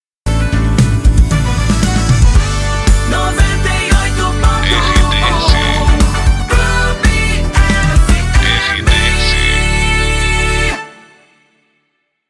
Passagem